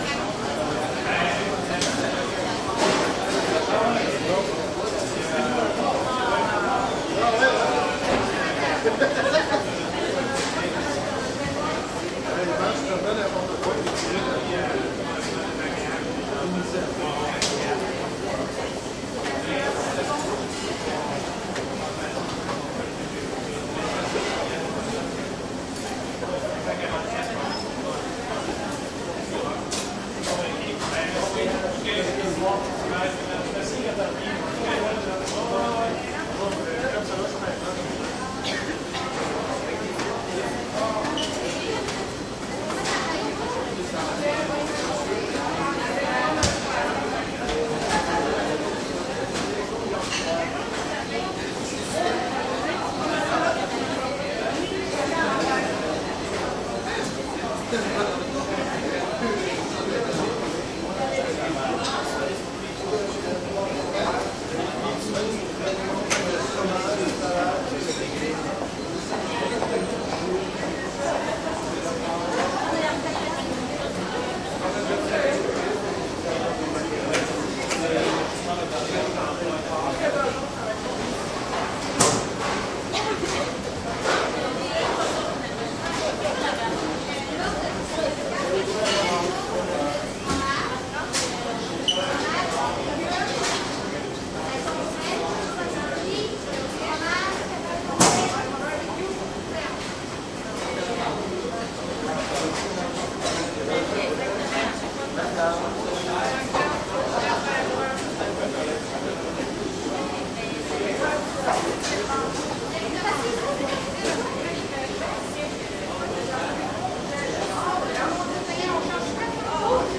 蒙特利尔 " 医院病婴病房
描述：医院病婴儿病房蒙特利尔，加拿大
Tag: 蒙特利尔 病房 婴儿 病人 医院 加拿大